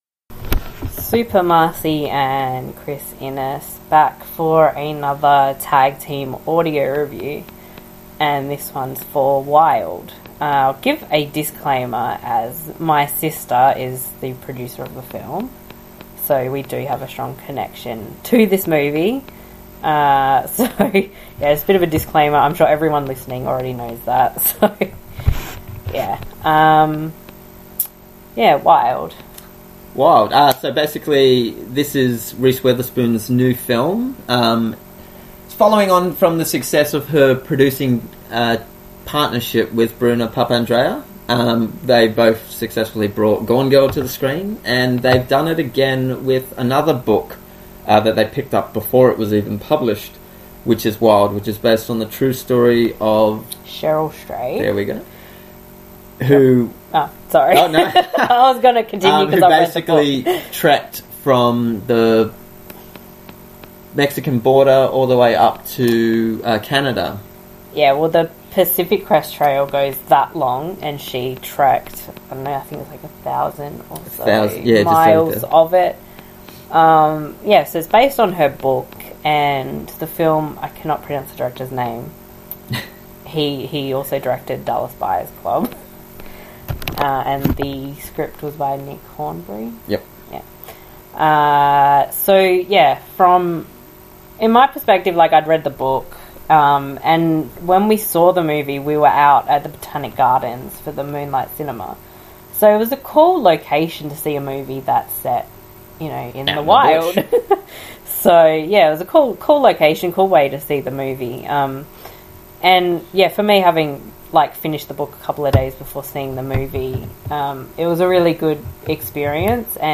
The following review is in audio format, with a back and forth discussion on the film, as well as the book.
wild-audio-review.mp3